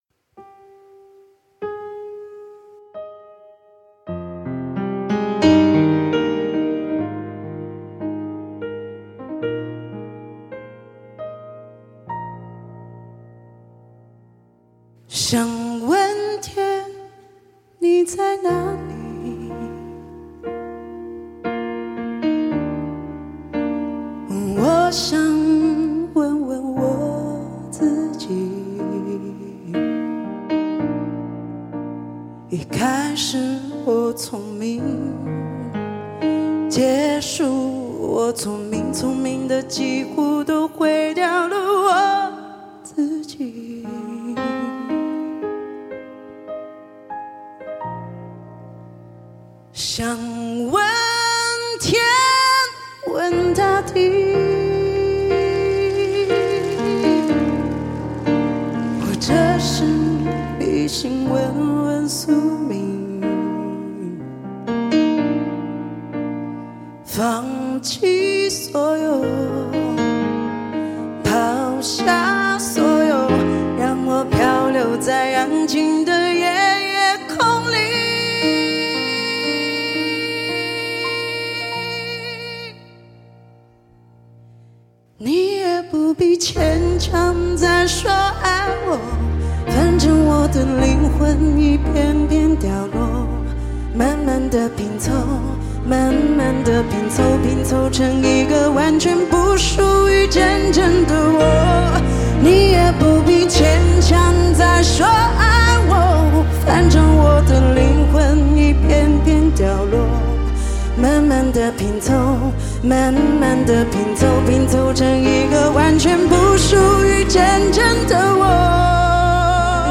精选汽车专用无损音质
发烧老情歌 纯音乐
极致发烧HI-FI人声测试碟